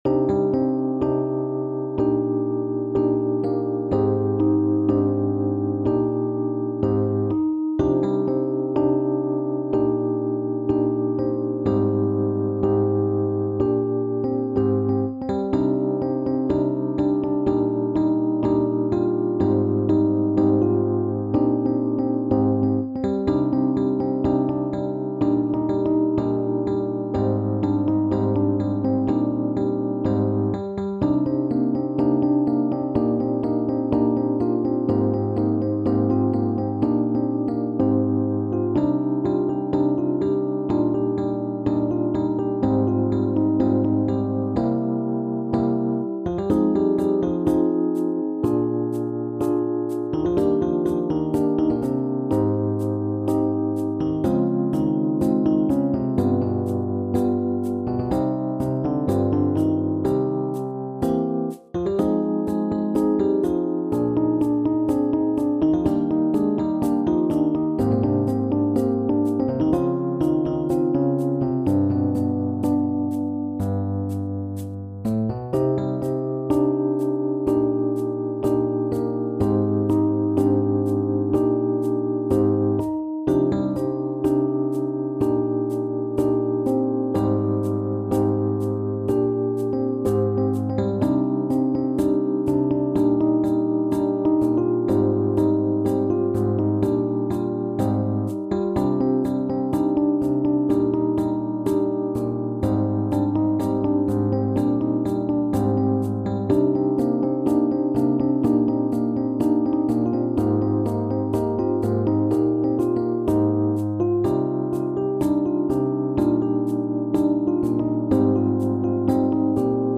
SSATTB | SSATTB avec solo | AATTBB avec solo